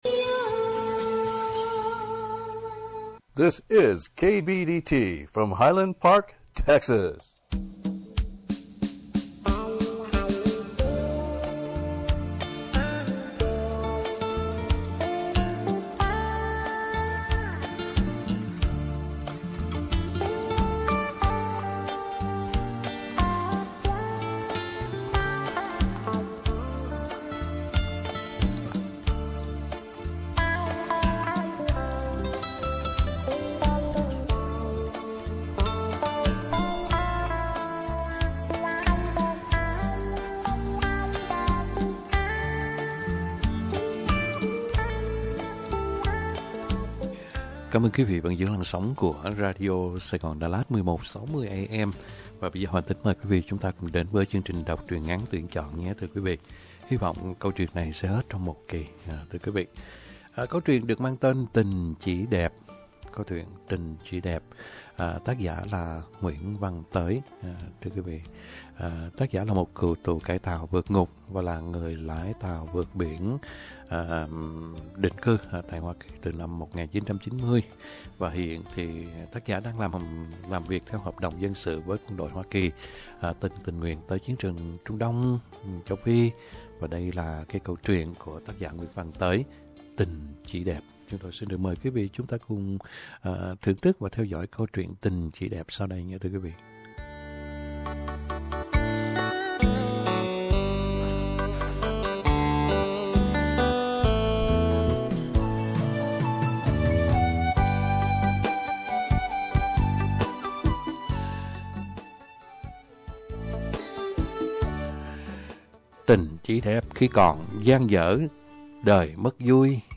Đọc Truyện = Tình Chỉ Đẹp - 11/15/2022 .